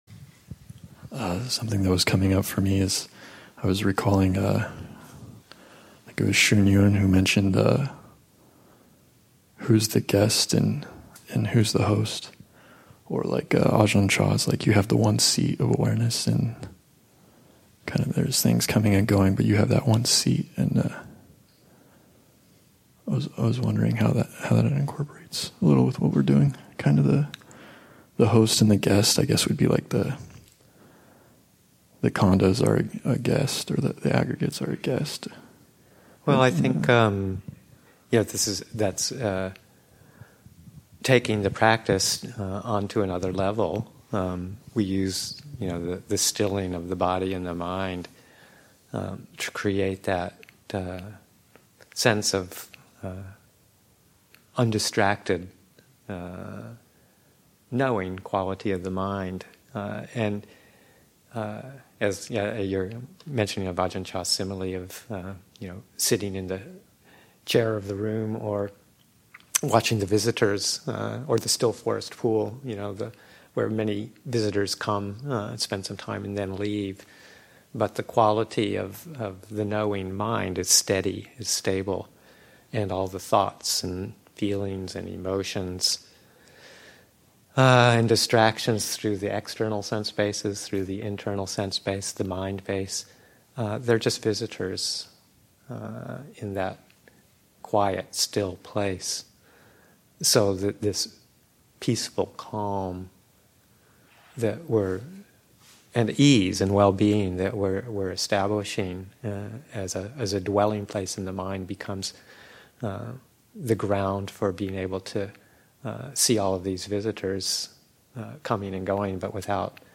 Ānāpānasati Daylong at Abhayagiri, Session 4 – Sep. 9, 2023